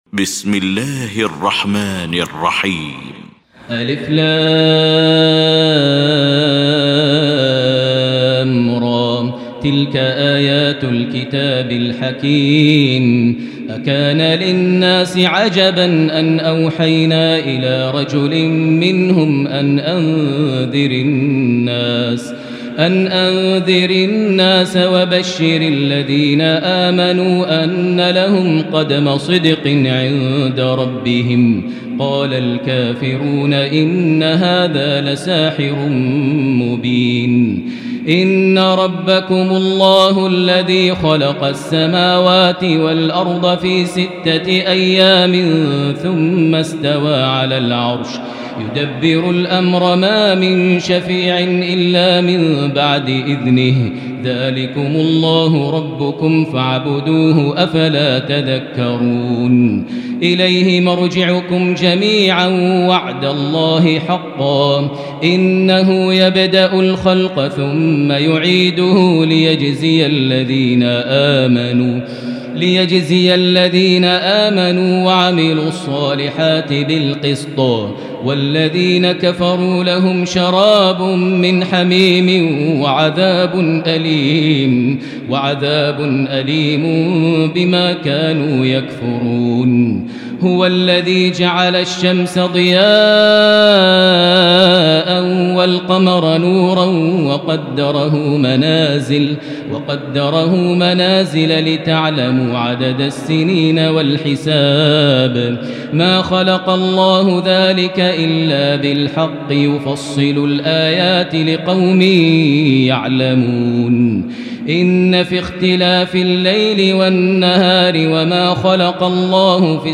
المكان: المسجد الحرام الشيخ: معالي الشيخ أ.د. بندر بليلة معالي الشيخ أ.د. بندر بليلة فضيلة الشيخ ماهر المعيقلي فضيلة الشيخ ياسر الدوسري يونس